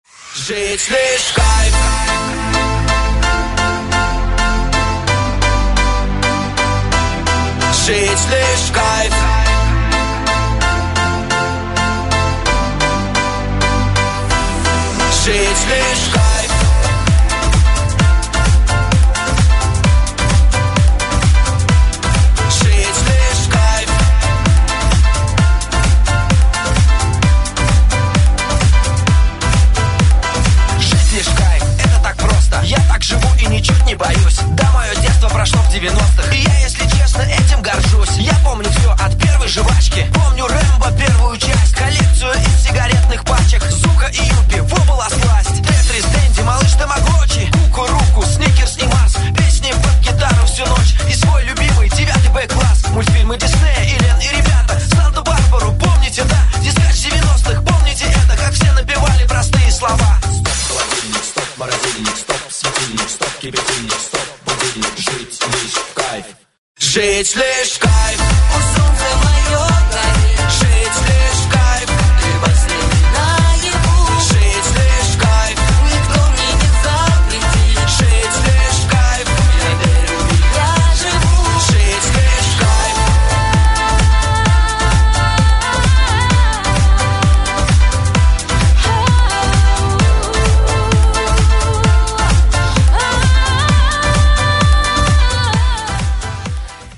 позитивные
мужской вокал
громкие
веселые
женский голос
90-е
шуточные
ностальгия